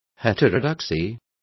Also find out how heterodoxias is pronounced correctly.